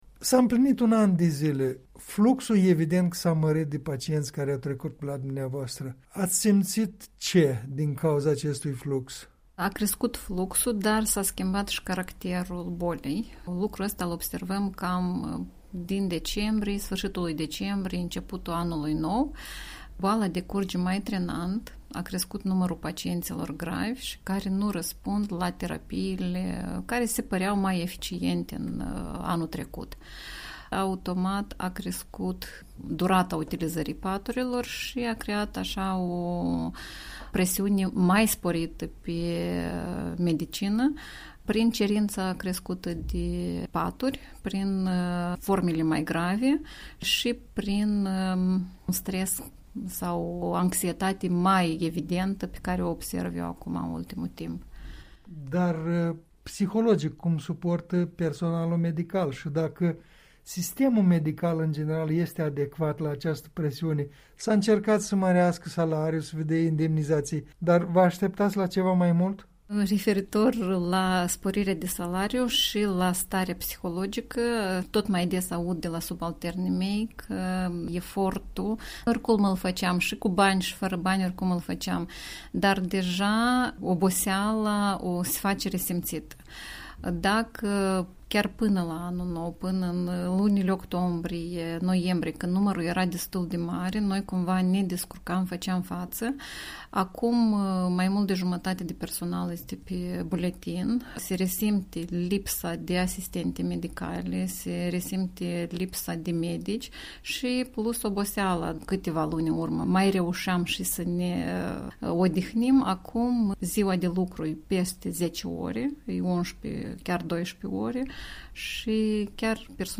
în dialog cu medicul